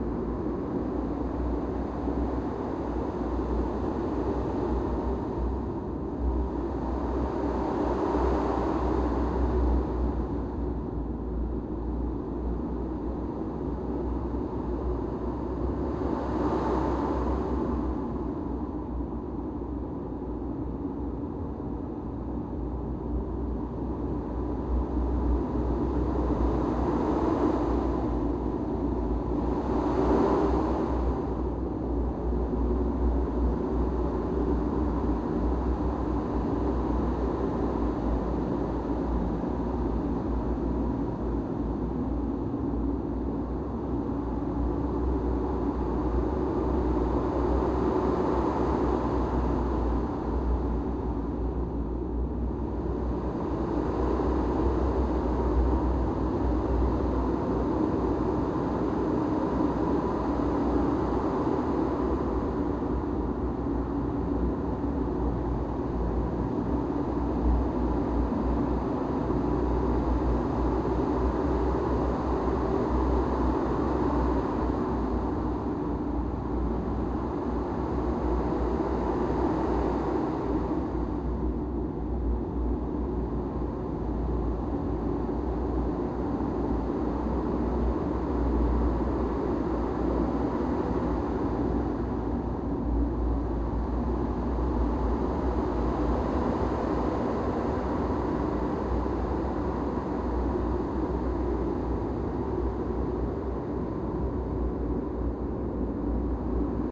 windShooterLoop.ogg